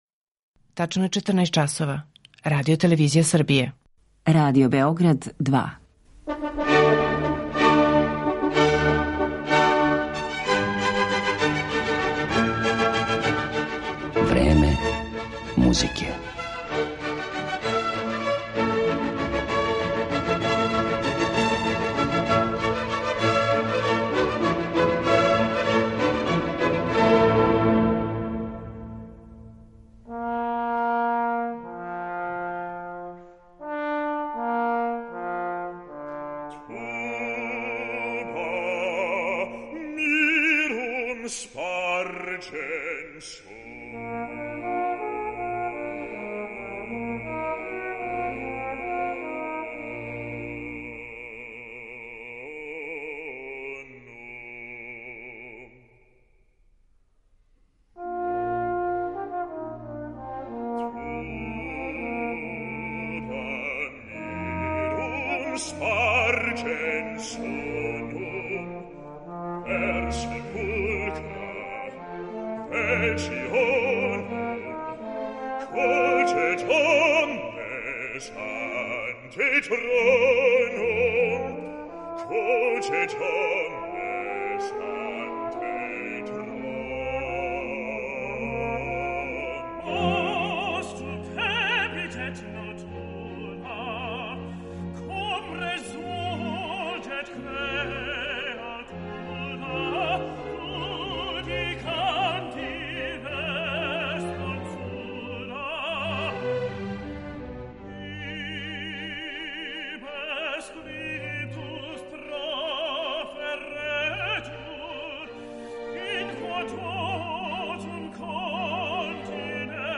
Па ипак, у касну јесен 1941. немачки нацисти су покушали да његово име и дело искористе у распламсавању патриотизма, посрнулог на Источном фронту, користећи чињеницу да је баш те зиме обележавана 150. годишњица Моцартове смрти. О догађајима којима је на територији Трећег рајха обележена та годишњица, као и тада достигнутим размерама злоупотебе уметности у сврху дневне политике, говорићемо у данашњем Времену музике , уз одломке Моцартовог Реквијема, Клавирског концерта у Це-дуру, Симфоније из Линца и опере „Тако чине све".